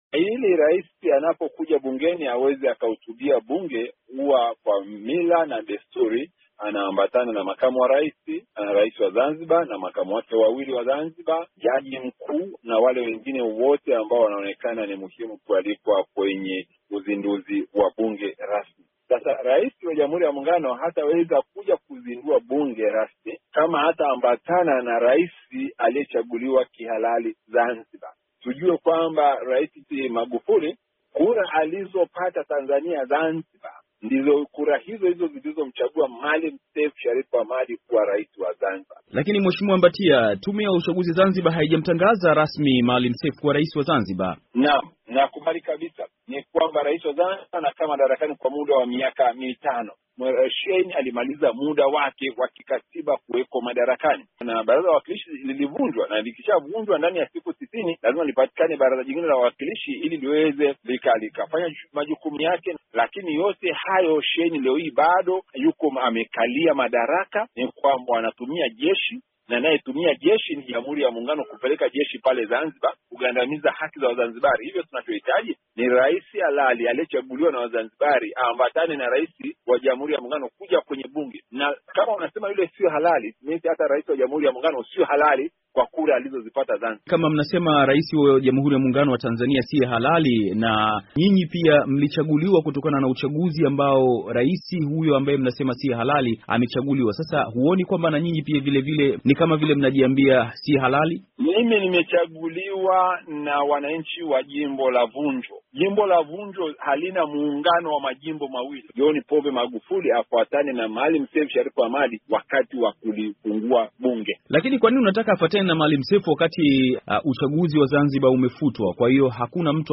Mahojiano na James Mbatia